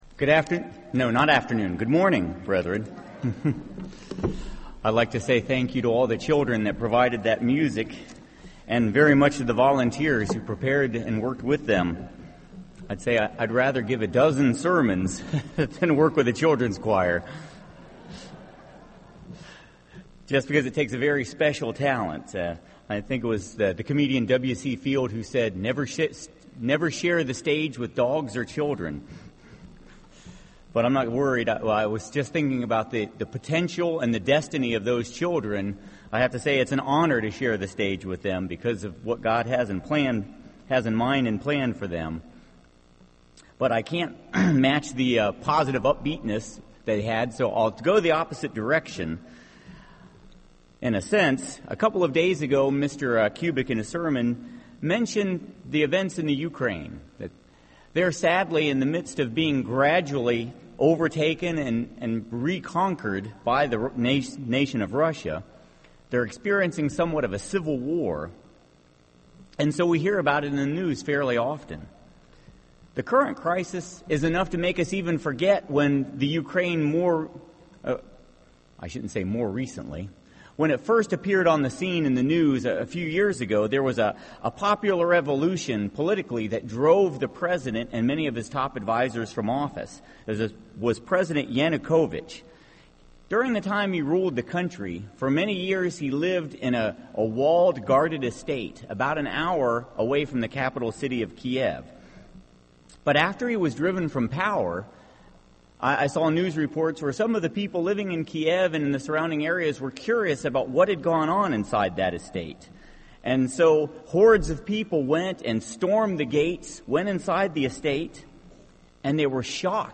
This sermon was given at the Jekyll Island, Georgia 2014 Feast site.